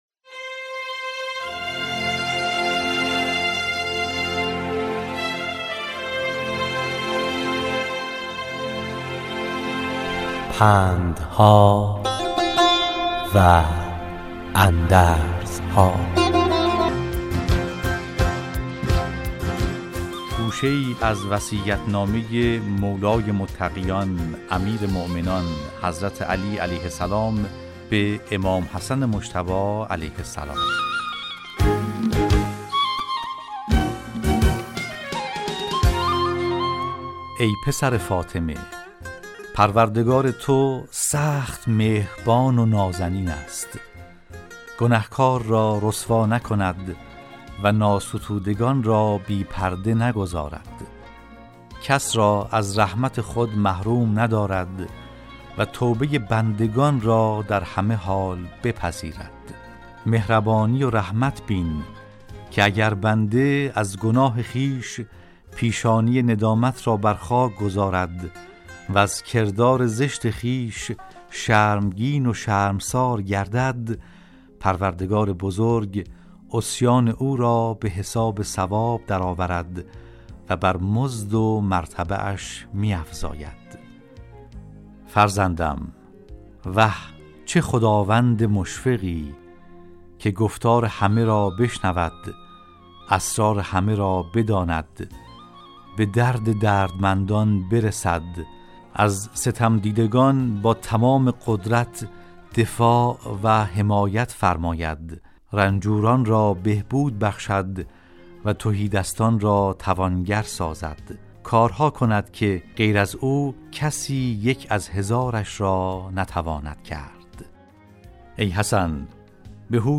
در برنامه " پندها و اندرزها"، راوی برای شنوندگان عزیز صدای خراسان، حکایت های پندآموزی را روایت می کند .